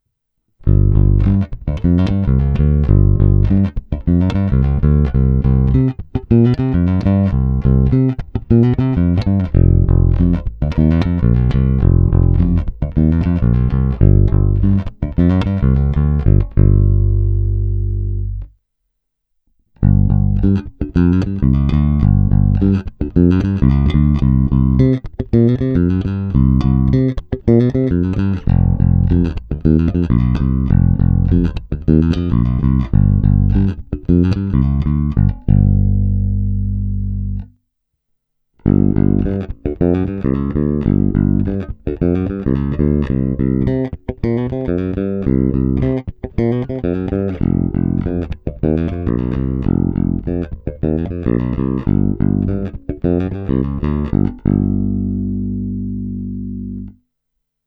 Hodně středů, a musím říct, že i hodně kovově ostrých výšek.
Není-li uvedeno jinak, následující nahrávky jsou provedeny rovnou do zvukové karty a s plně otevřenou tónovou clonou.